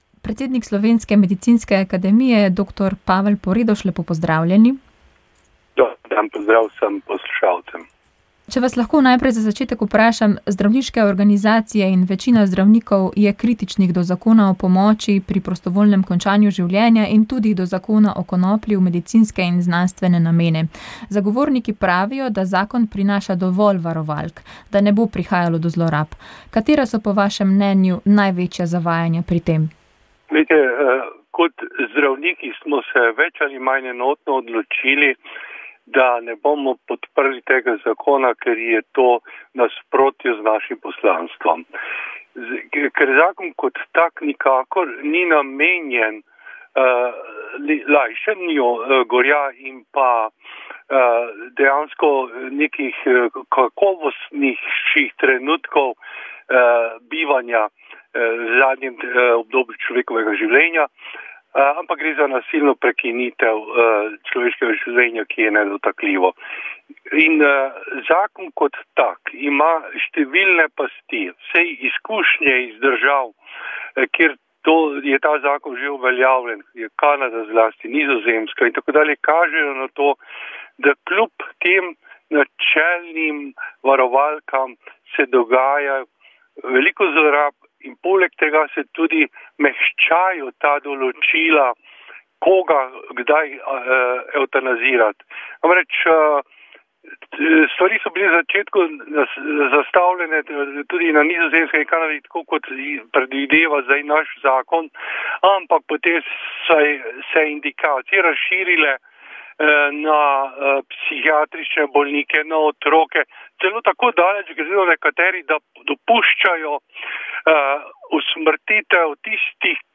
Govor predsednika Republike Slovenije Boruta Pahorja na pogrebu Ivana Omana
Več sto ljudi se je v Škofji Loki zbralo na pogrebu enega ključnih osamosvojiteljev Slovenije in soustanovitelja Slovenske kmečke zveze Ivana Omana. Kot je v nagovoru zbranim na pogrebni slovesnosti z vojaškimi častmi izpostavil predsednik republike Borut Pahor, je bil Oman naš narodni buditelj.